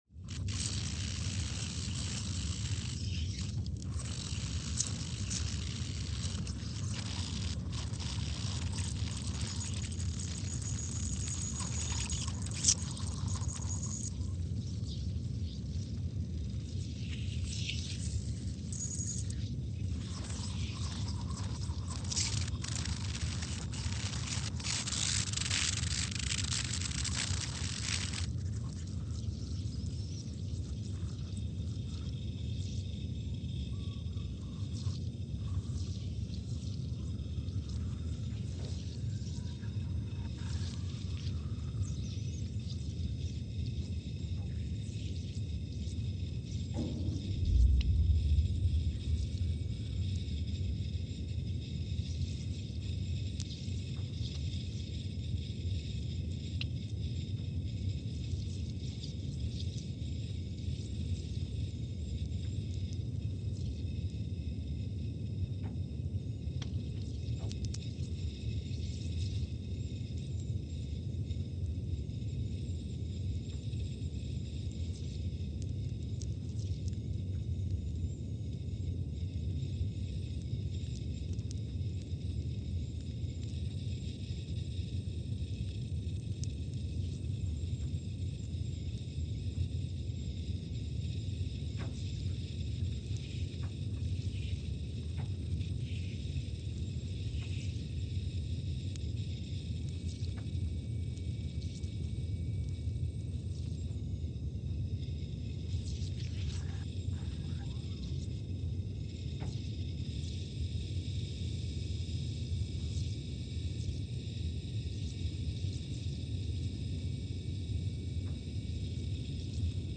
Scott Base, Antarctica (seismic) archived on December 15, 2022
Sensor : CMG3-T
Speedup : ×500 (transposed up about 9 octaves)
Loop duration (audio) : 05:45 (stereo)
SoX post-processing : highpass -2 90 highpass -2 90